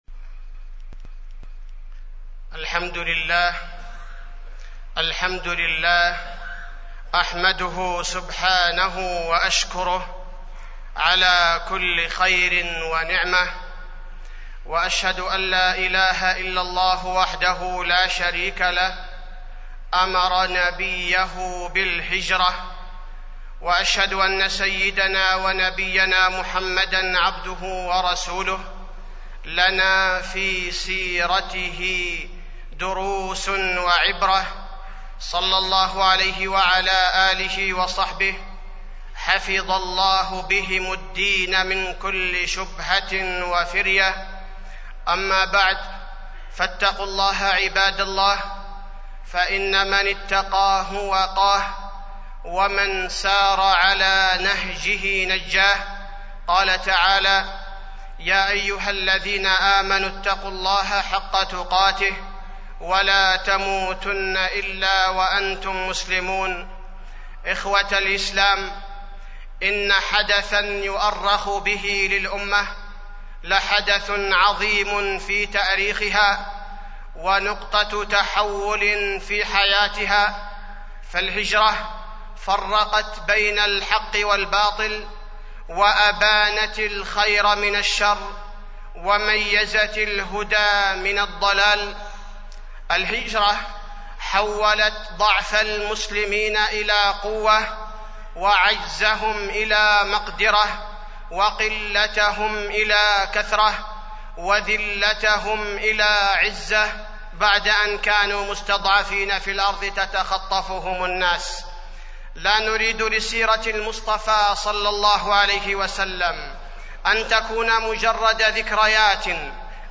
تاريخ النشر ٩ ربيع الأول ١٤٢٧ هـ المكان: المسجد النبوي الشيخ: فضيلة الشيخ عبدالباري الثبيتي فضيلة الشيخ عبدالباري الثبيتي الهجرة